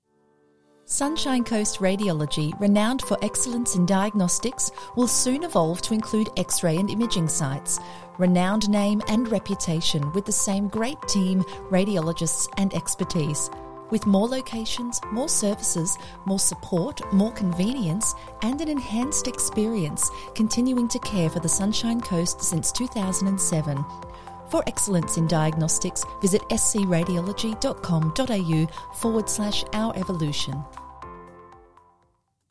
Female
English (Australian)
Corporate
Medical Imaging Advertising
Words that describe my voice are Colourful, Friendly, Sincere.